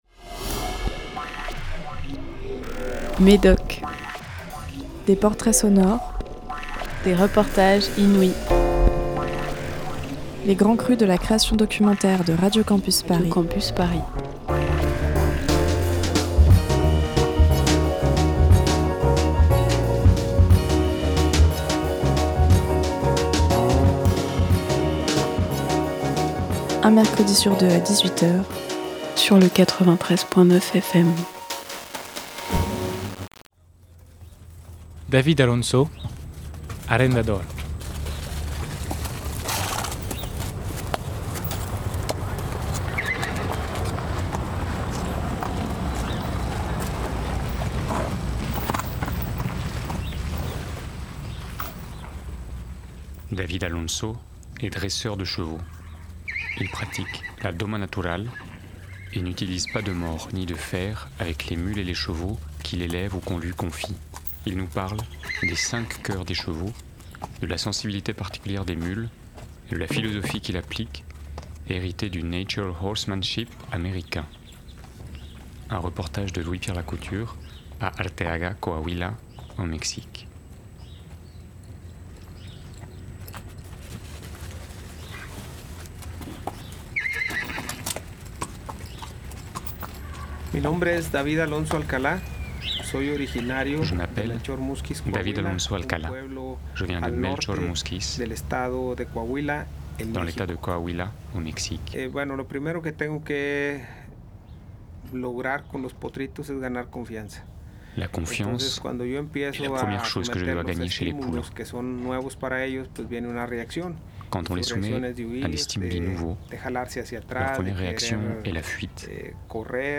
*Médoc, c'est l'émission de documentaires de création sonore de Radio Campus Paris, des mercredis aléatoires à 18h.